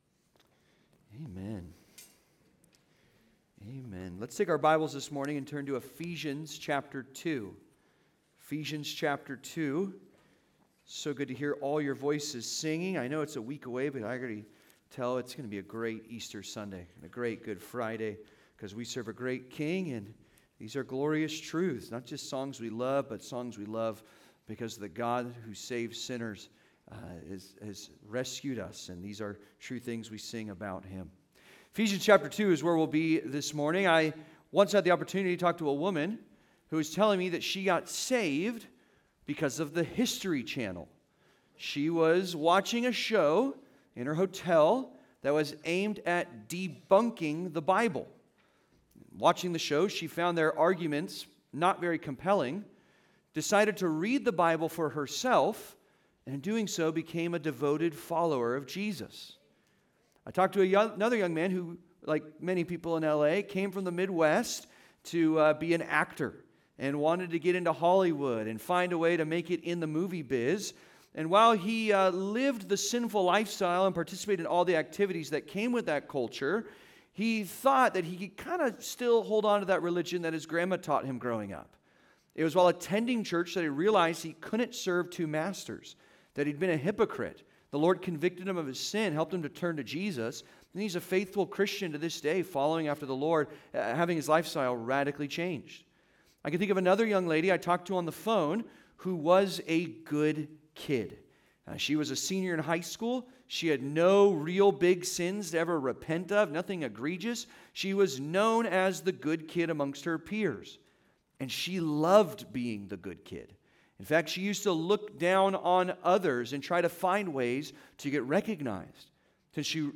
Dead Men Walking (Sermon) - Compass Bible Church Long Beach